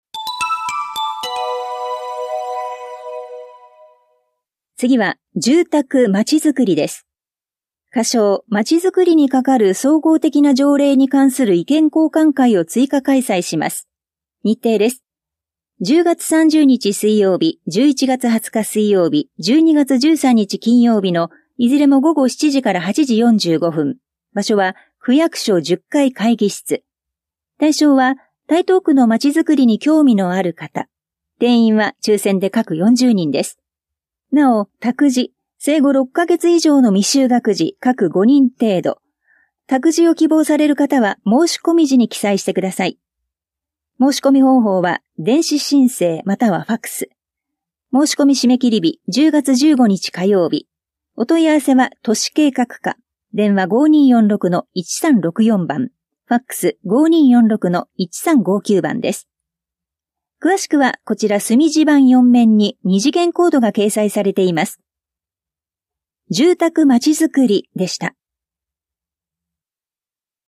広報「たいとう」令和6年10月5日号の音声読み上げデータです。